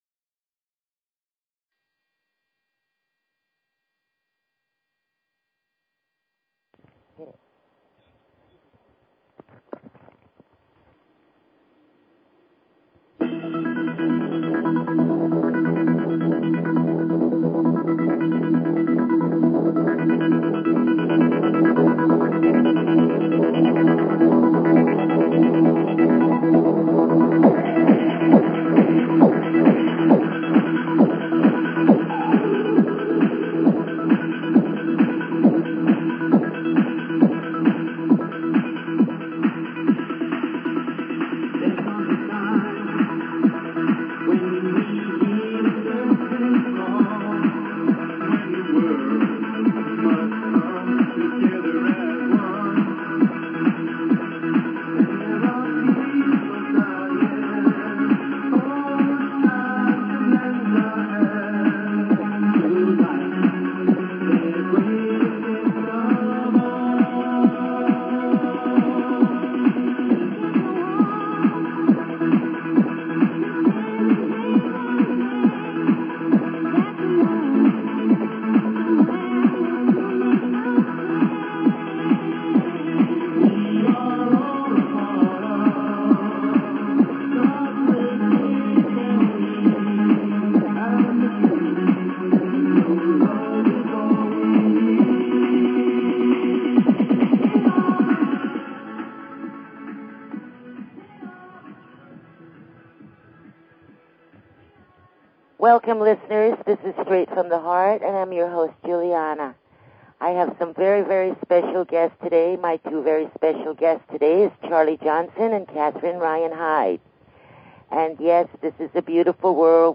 Talk Show Episode, Audio Podcast, Straight_from_the_Heart and Courtesy of BBS Radio on , show guests , about , categorized as